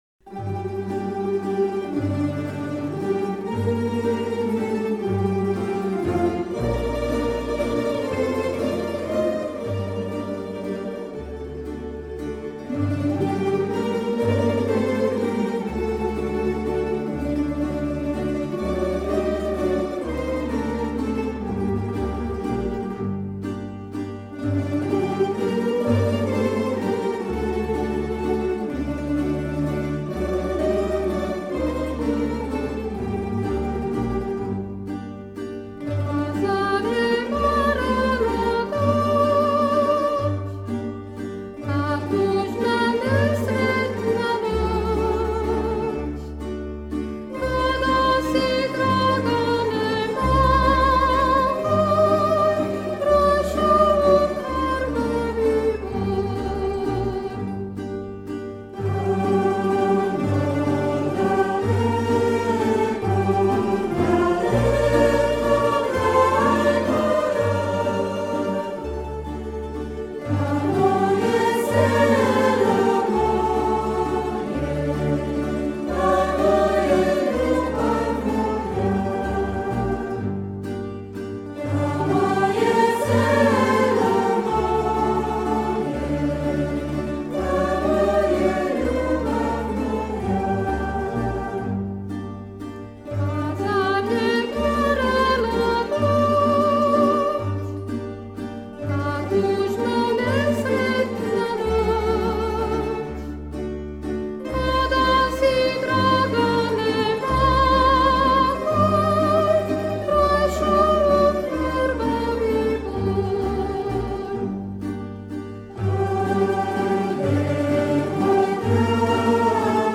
Genre: Volksmusik